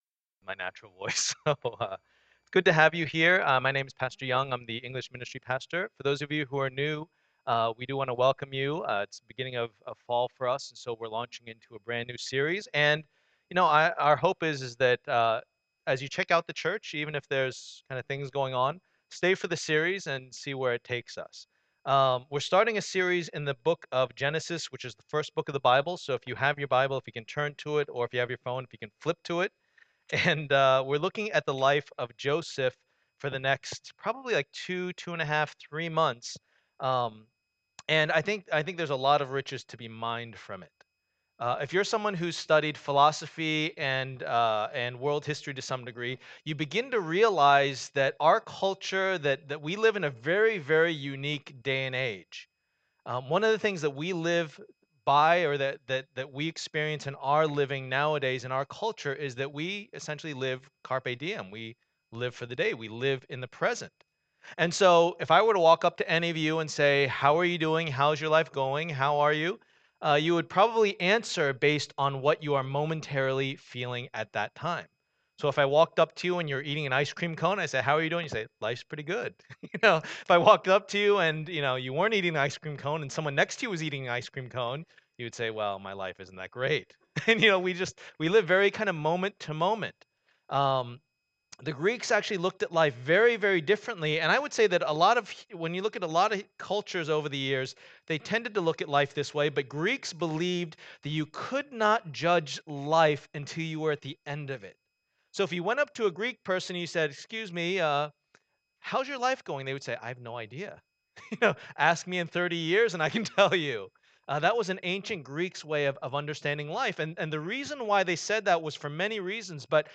Passage: Genesis 37:1-11 Service Type: Lord's Day